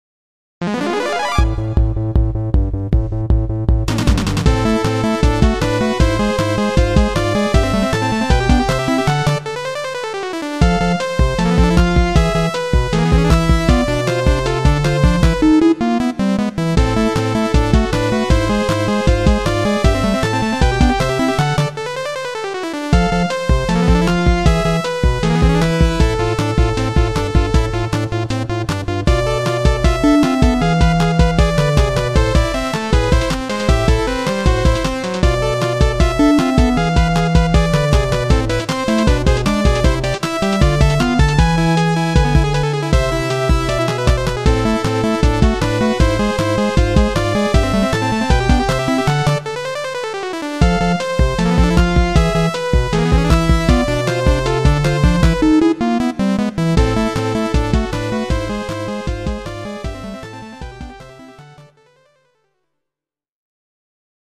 GS音源。